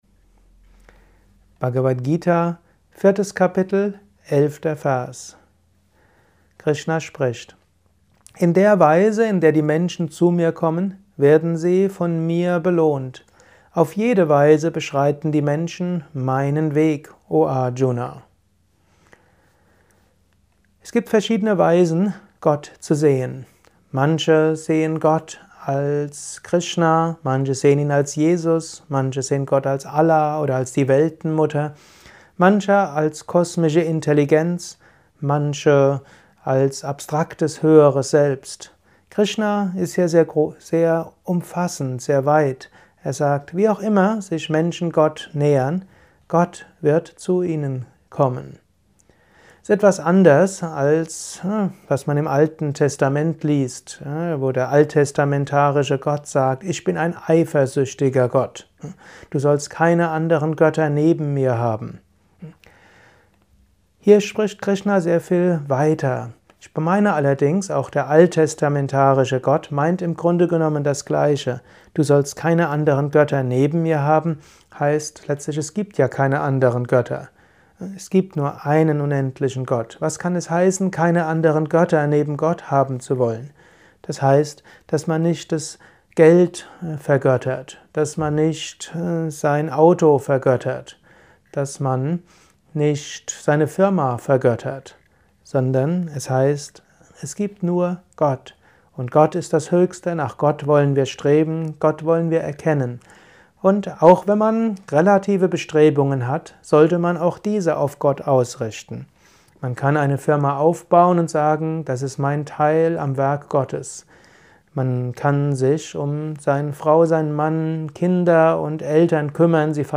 Dies ist ein kurzer Kommentar als Inspiration für den heutigen
Aufnahme speziell für diesen Podcast.